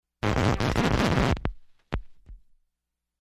На этой странице собрана коллекция аудиозаписей, воссоздающих характерное звучание старого граммофона: потрескивание виниловой пластинки, скрип запускаемой иглы и тот самый теплый, аналоговый тембр.
Игла граммофона съехала с дорожки